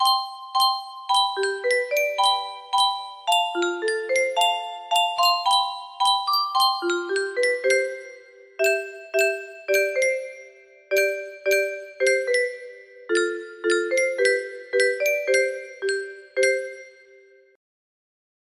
彈球核心817 music box melody
Full range 60